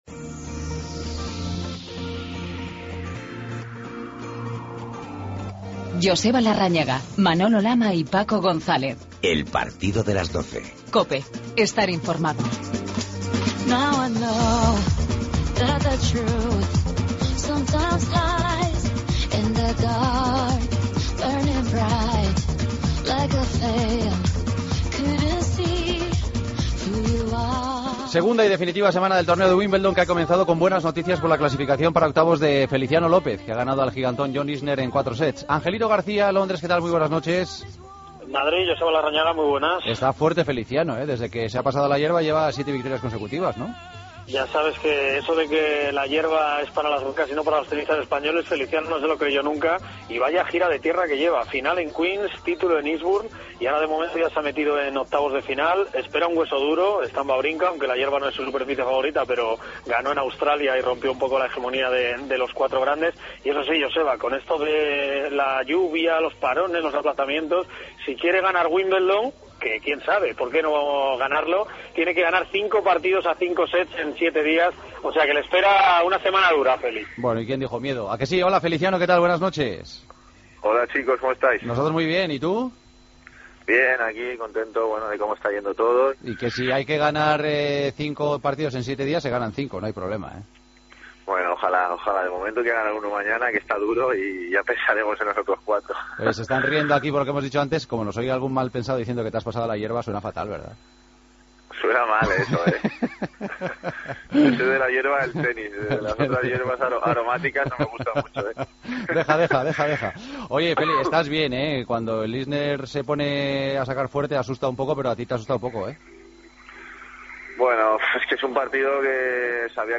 AUDIO: Entrevista a Feliciano López, que hoy se ha metido en octavos de Wimbledon. La agenda del día.